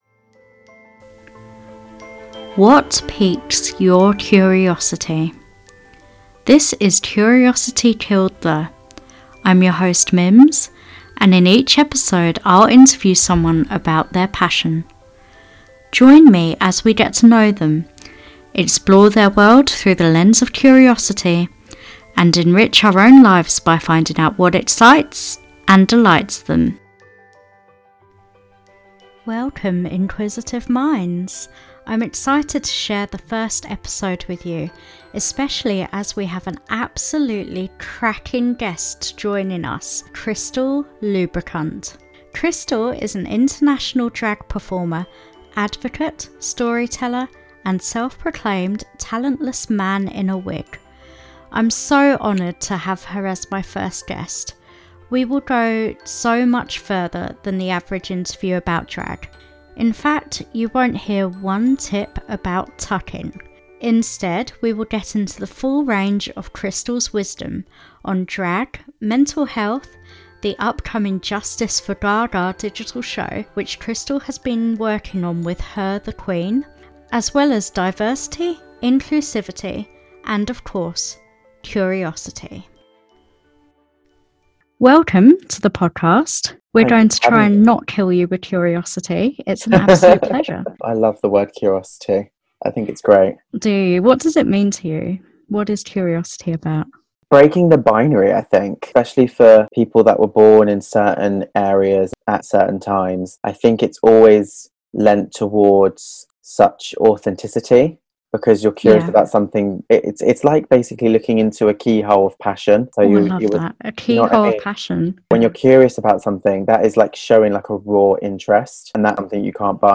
This is not your average drag queen interview, we dive deep and talk about: